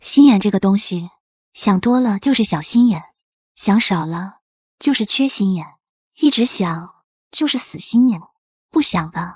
High-Energy Motivational Fitness Coach AI Voice
Transform your fitness content with a powerful, natural-sounding AI voice designed to inspire, push limits, and drive results in every workout session.
Text-to-Speech
High Intensity
Motivational Tone